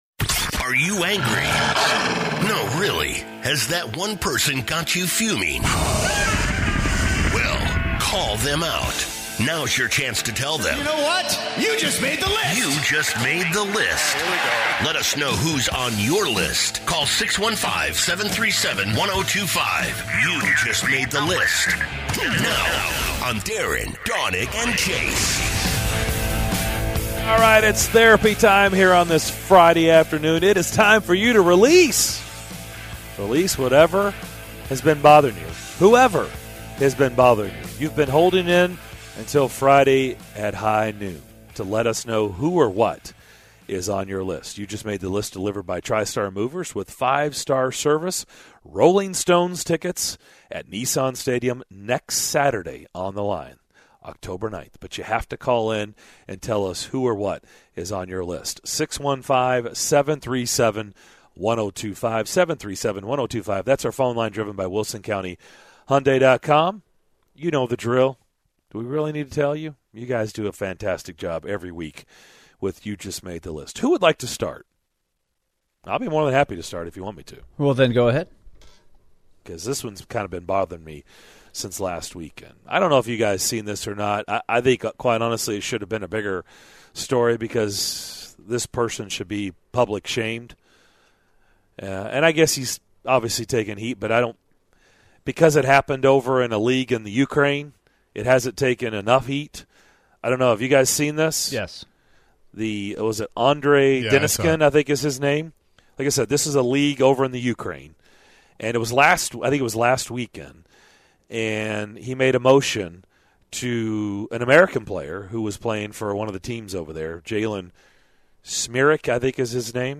This hour we play 'You Just Made the List'! Hear this week's edition to what the guys and the callers get off their chest.